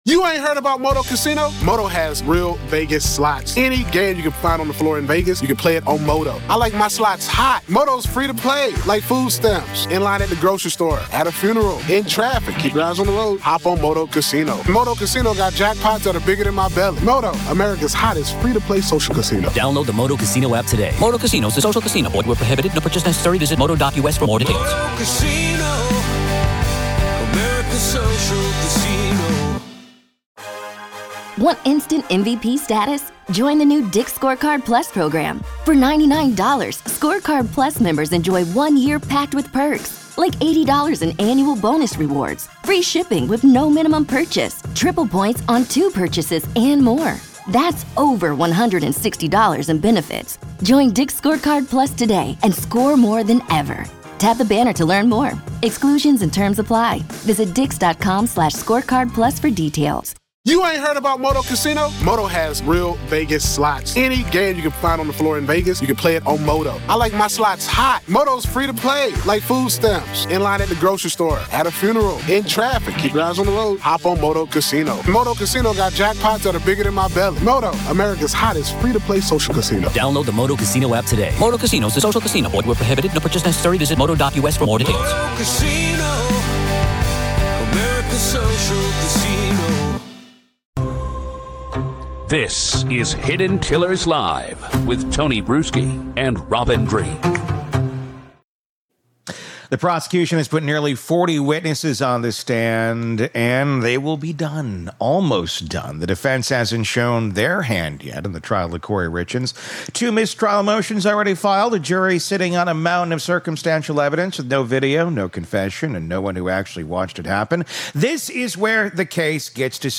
Kouri Richins Defense Strategy Exposed — Expert Panel Breaks It Down
That's the question this expert panel is built to answer.